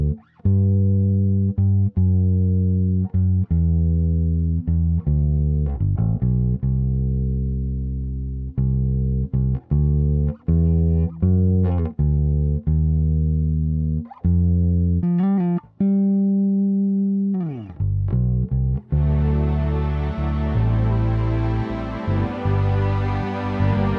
Minus Piano Pop (1980s) 2:50 Buy £1.50